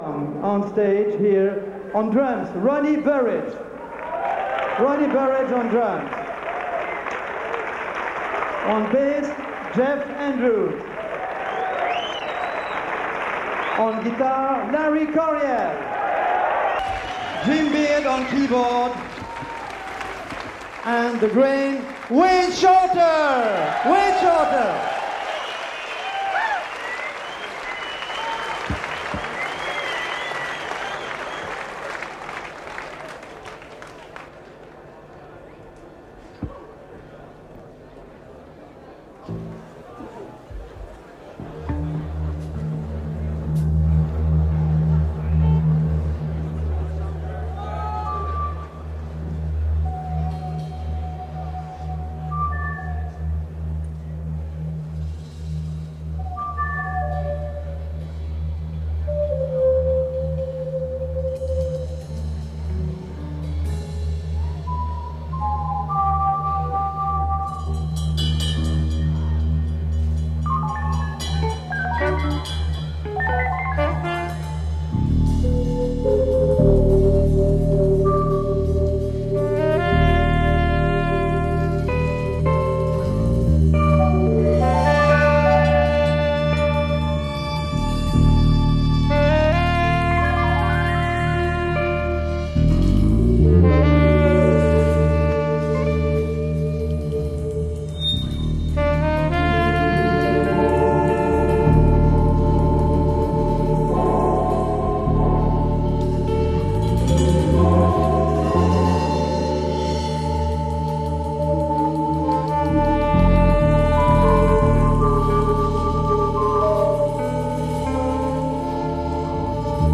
Live at Montreux Jazz Festival
in a concert recorded live at Montreux on July 20, 1990.
jazz electric guitar